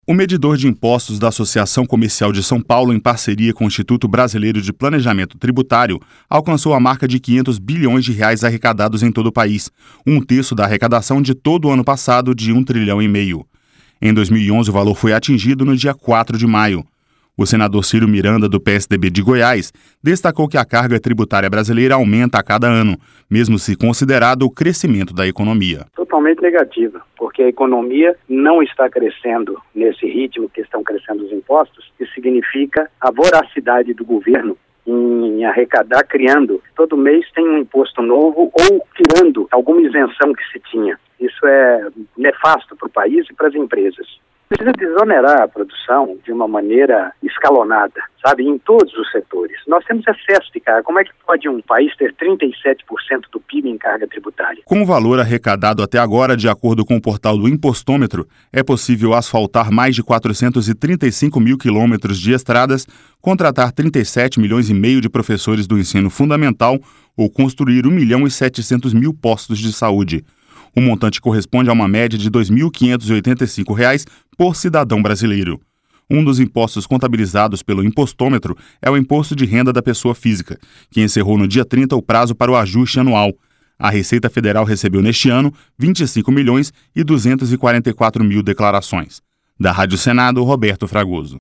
O senador Cyro Miranda, do PSDB de Goiás, destacou que a carga tributária brasileira aumenta a cada ano, mesmo se considerado o crescimento da economia.